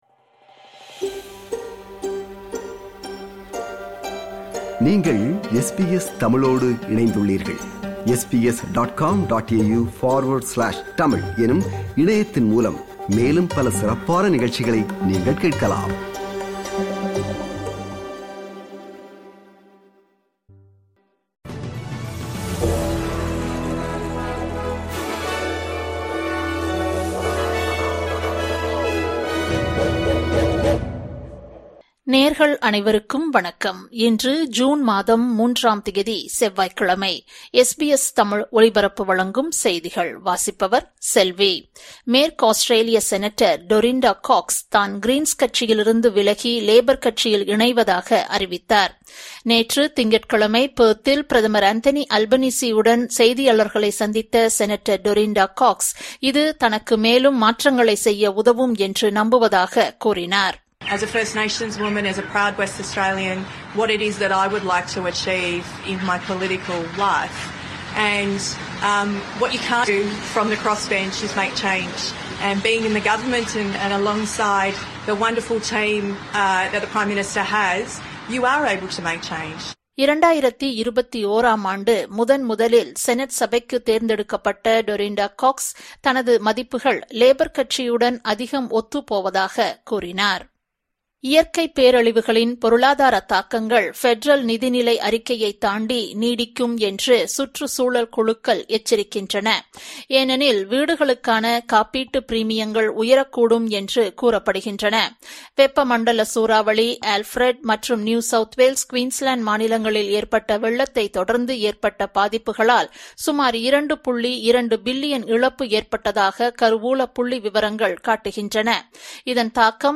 SBS தமிழ் ஒலிபரப்பின் இன்றைய (செவ்வாய்க்கிழமை 03/06/2025) செய்திகள்.